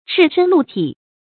赤身露體 注音： ㄔㄧˋ ㄕㄣ ㄌㄨˋ ㄊㄧˇ 讀音讀法： 意思解釋： 指露出大部分身子，也指全身裸露，一絲不掛。